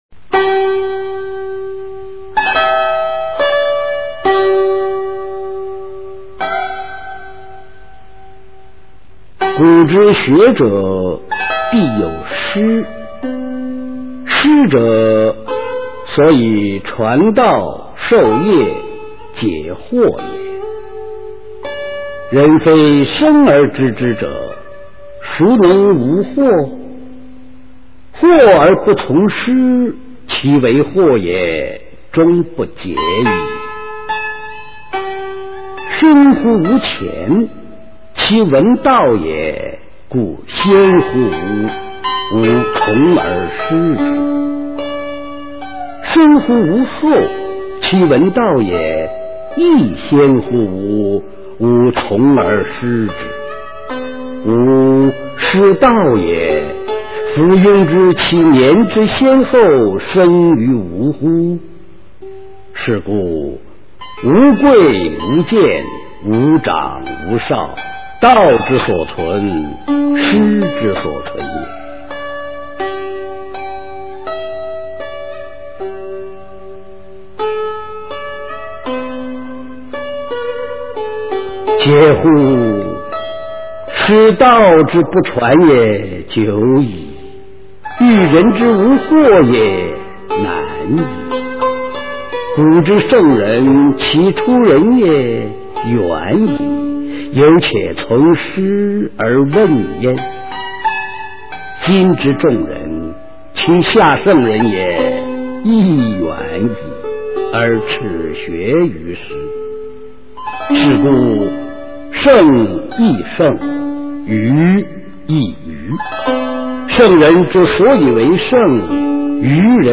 韩愈《师说》原文与译文（含配乐朗读）